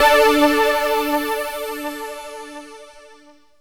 SYNTH GENERAL-2 0001.wav